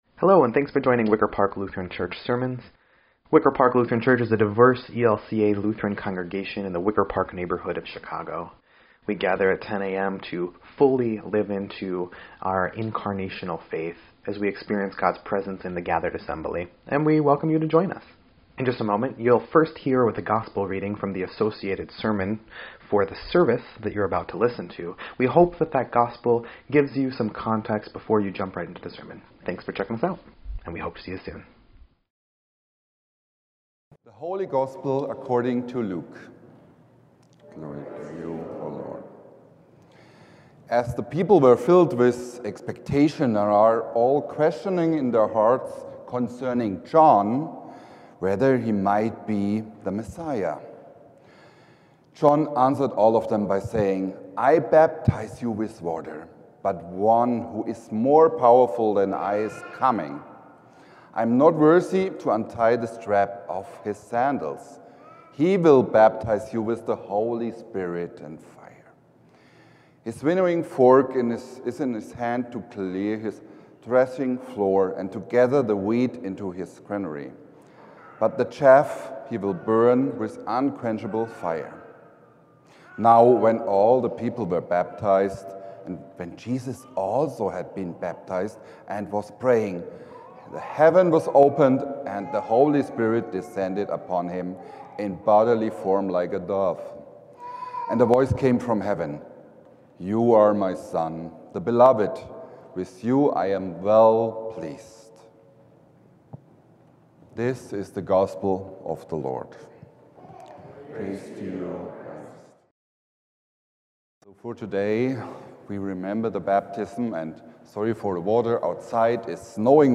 1.12.25-Sermon_EDIT.mp3